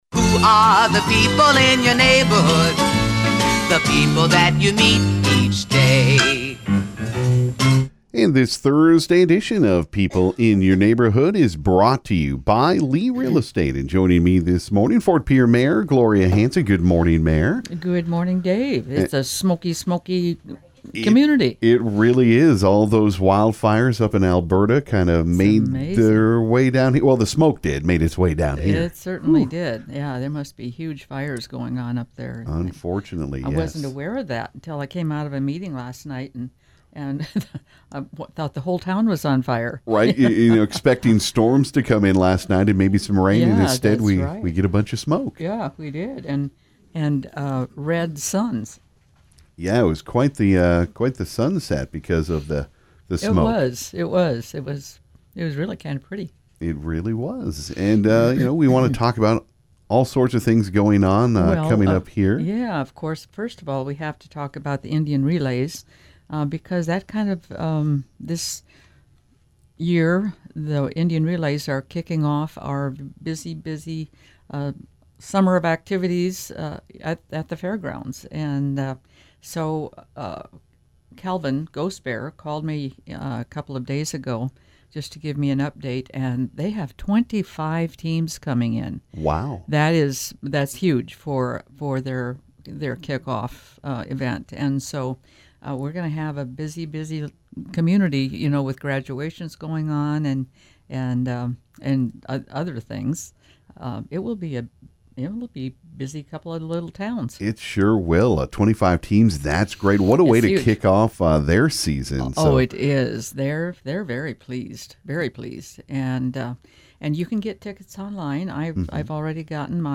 This morning on People In Your Neighborhood the Mayor of Ft. Pierre, Gloria Hanson, stopped into the KGFX Studio.